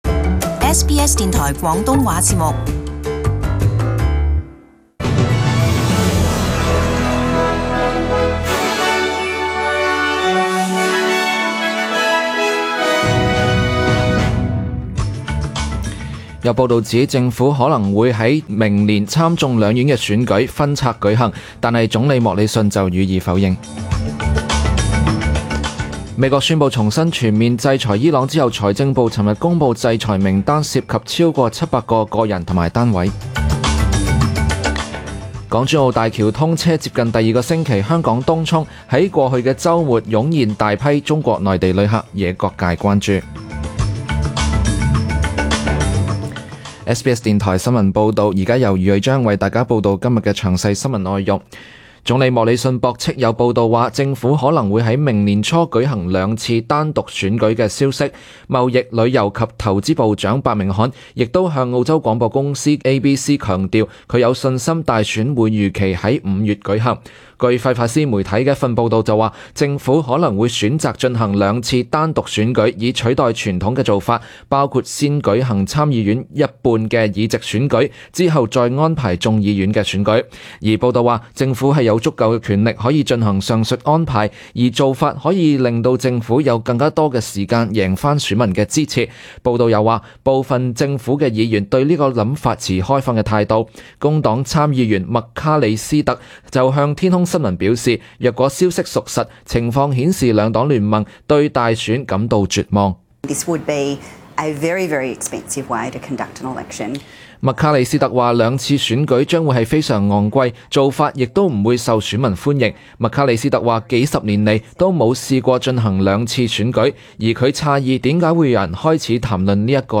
SBS中文新聞 （十一月六日）